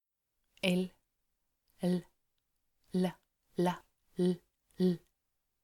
Lyt til [l], lyt til [ð].